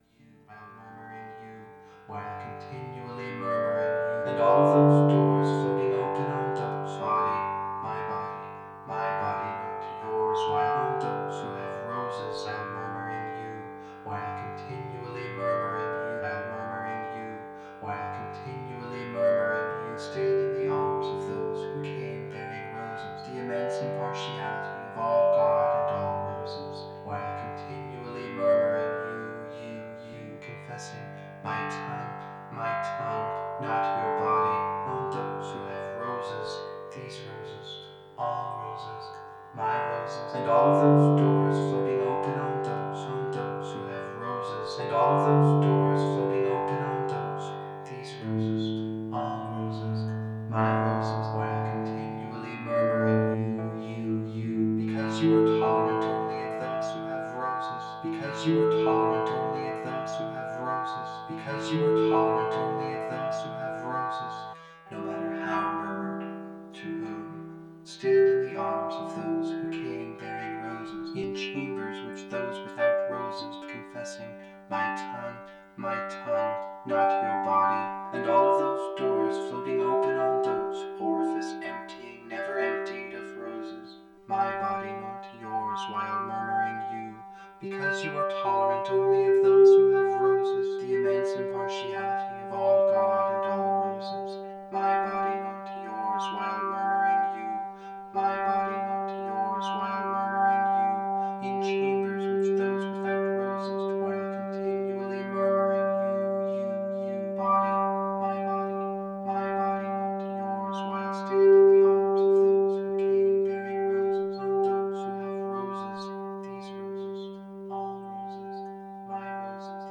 Source: Random text segments (6:35-8:52)